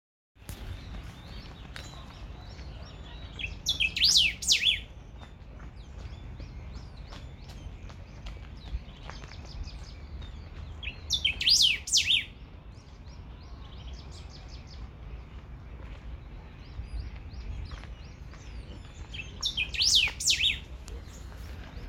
Golden-billed Saltator (Saltator aurantiirostris)
Location or protected area: Reserva Ecológica Costanera Sur (RECS)
Condition: Wild
Certainty: Observed, Recorded vocal
PEPITERO-DE-COLLAR.mp3